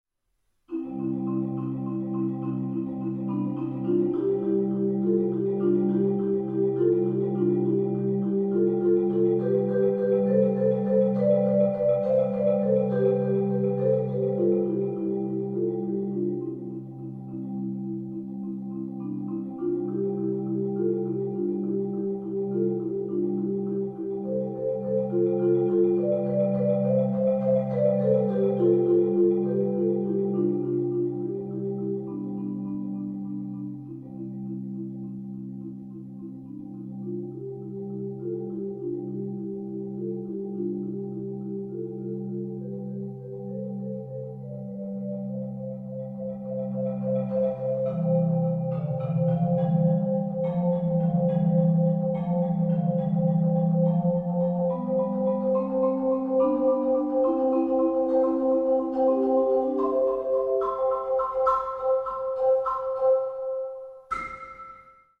Genre: Marimba (4-mallet)
# of Players: 1
Marimba (4.3-octave)